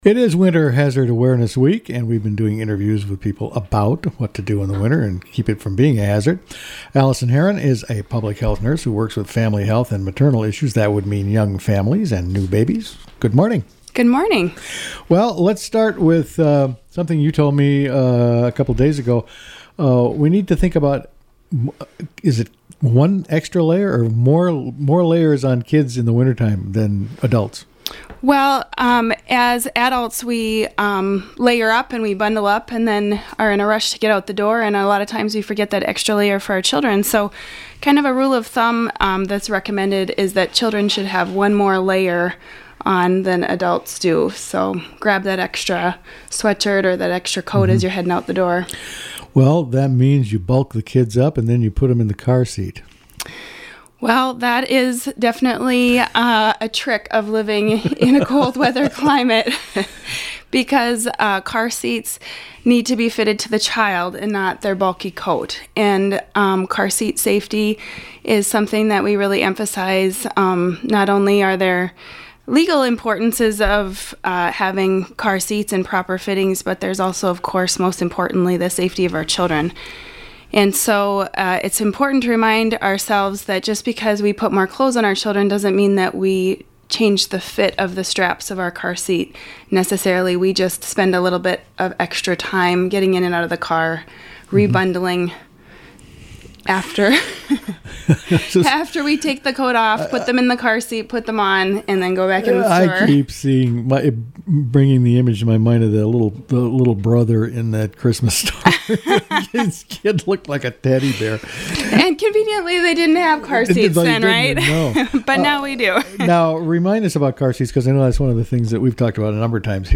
In the last of our Winter Hazard Awareness Week interviews